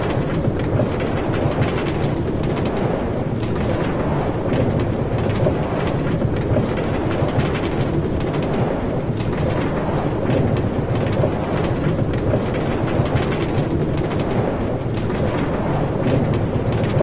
roll-pygbag.ogg